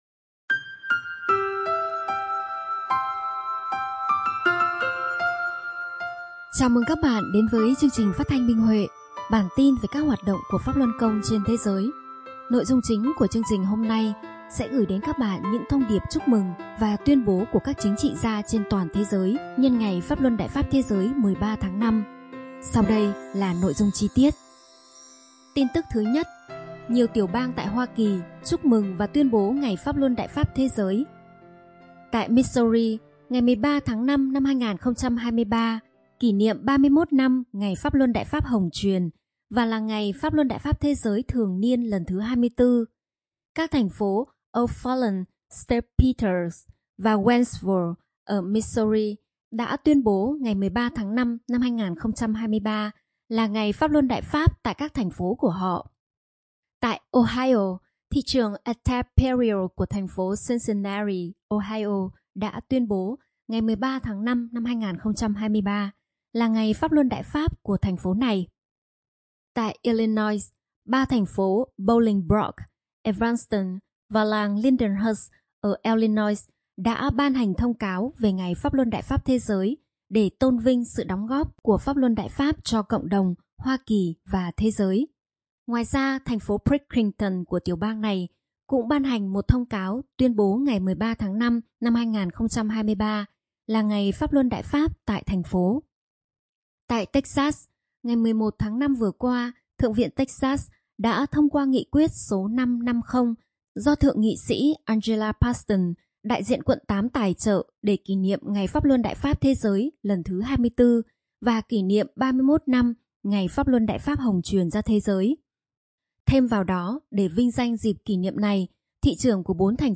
Chương trình phát thanh số 29: Tin tức Pháp Luân Đại Pháp trên thế giới – Ngày 19/5/2023